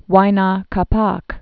(wīnä kä-päk) Died c. 1525.